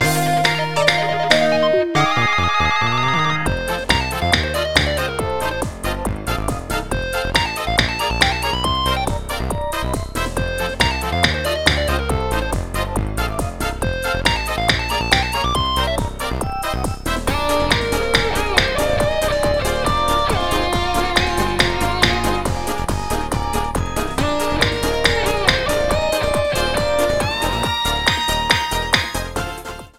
Jumping music notes and music